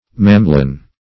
Search Result for " mamelon" : The Collaborative International Dictionary of English v.0.48: Mamelon \Mam"e*lon\, n. [F.]